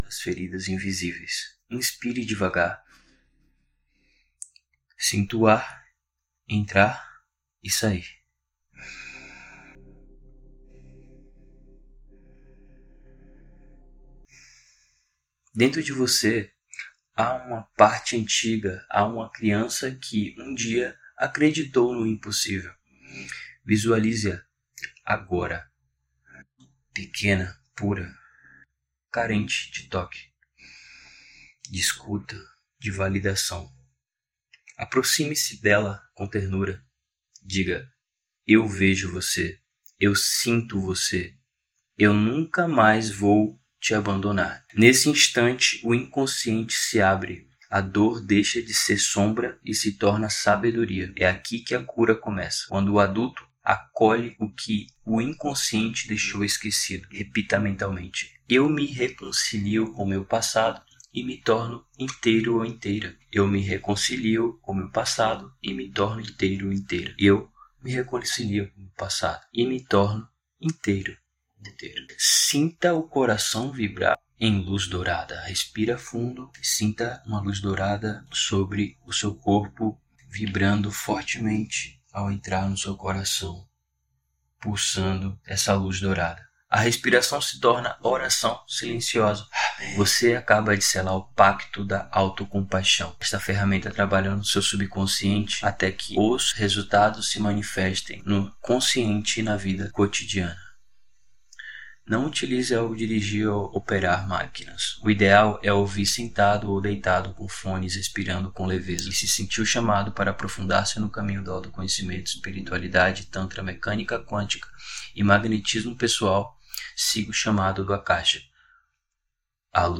Meditação
Audiomeditação_Cura_das_Feridas_Invisiveis_krzkdw.wav